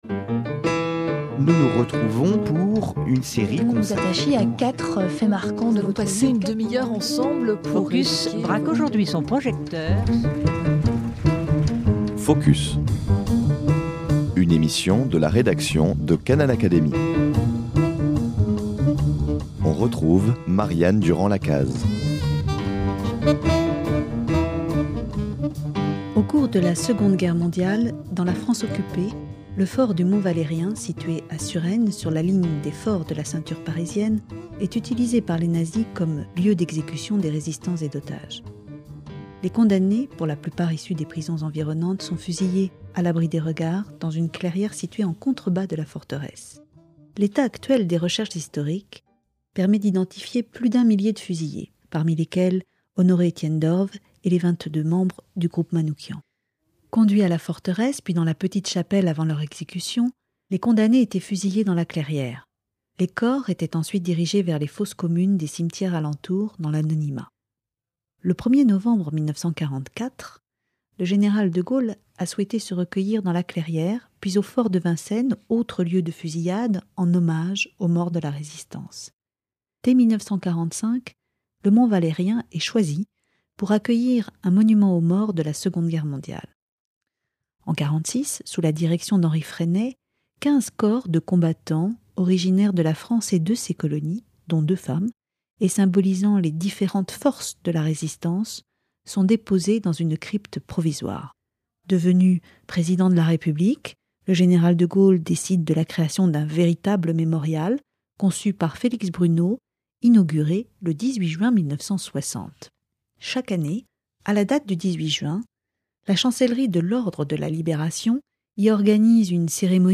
retrouvez au micro de Canal Académie, face aux œuvres